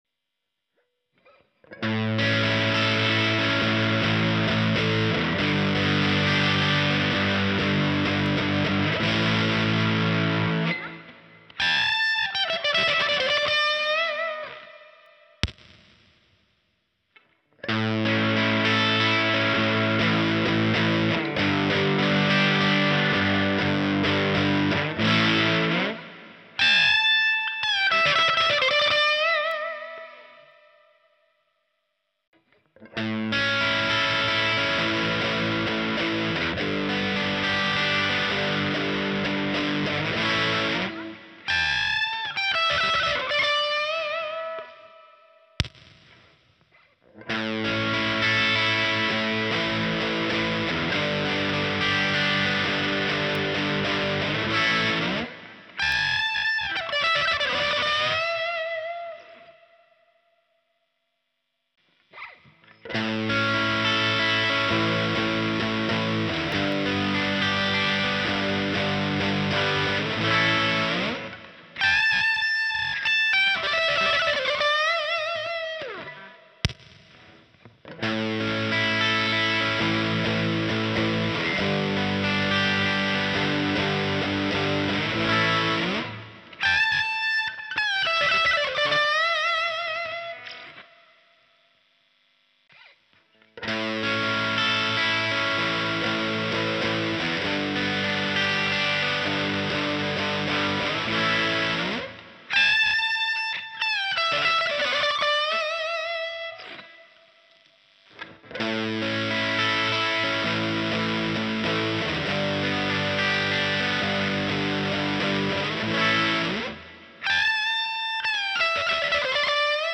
とりあえず、音色のサンプルです。
POD X3のクリーンを介して録音してます。
クリップ無し9V・クリップ無し17V・LEDクリップ9V・LEDクリップ17V・
クリップなしはかなりワイルドで、9Vは良いのですが17Vだとあまり歪まず、このままだと使いにくそうです。LEDクリップは鋭い感じの歪み、ダイオードクリップはいわゆるTS系のマイルドな音で、クリップが強くなるほど電圧の差は少なくなります。
少し低音の割れ方が違う感じです。